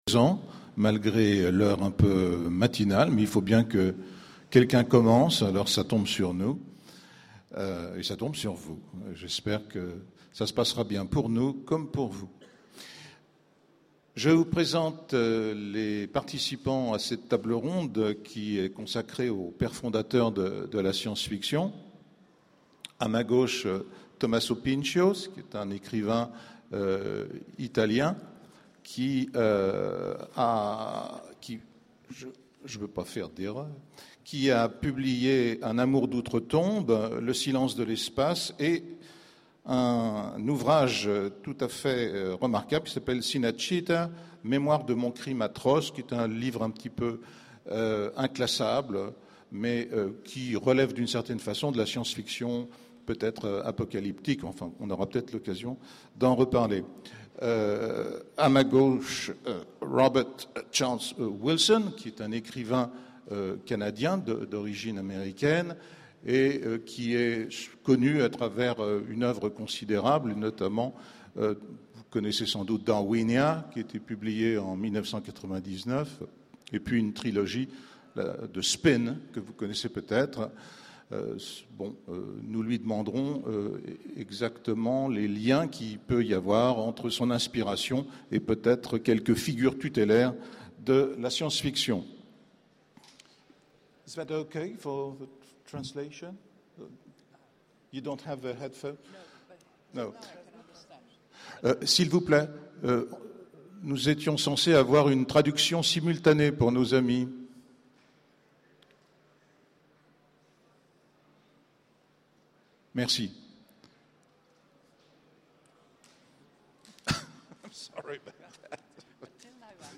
Utopiales 12 : Conférence Les Pères fondateurs